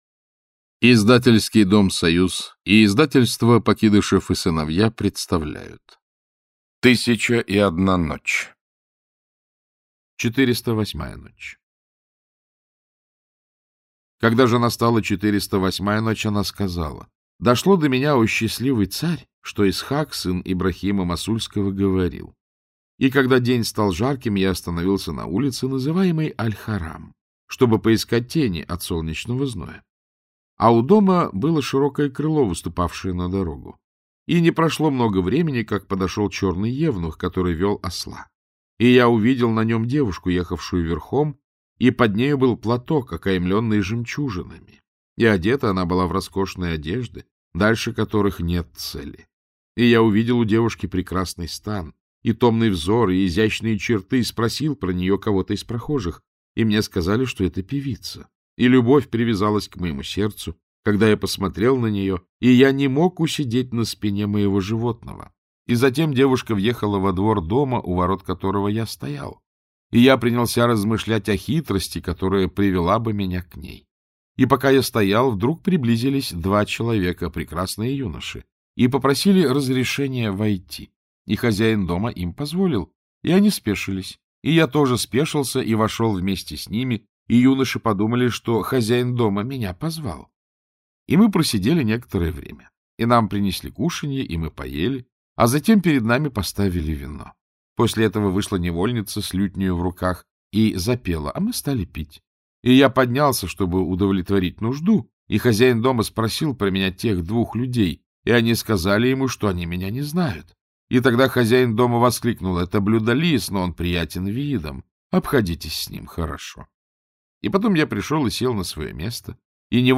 Аудиокнига Сказки тысячи и одной ночи. Ночи 408-458 | Библиотека аудиокниг
Aудиокнига Сказки тысячи и одной ночи. Ночи 408-458 Автор Сборник Читает аудиокнигу Александр Клюквин.